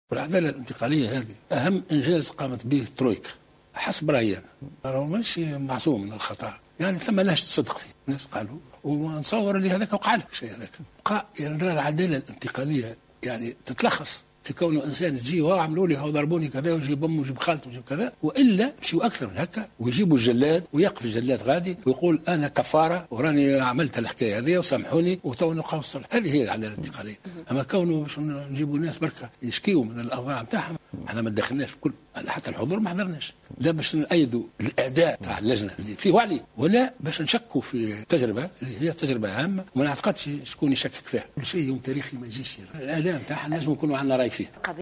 اعتبر رئيس الجمهورية الباجي قائد السبسي في حوار تلفزي اليوم الثلاثاء، في تعليقه على جلسات الاستماع التي نظمتها هيئة الحقيقة والكرامة، أن ملف العدالة الانتقالية كان أبرز إنجاز قامت به الترويكا بقيادة حركة النهضة.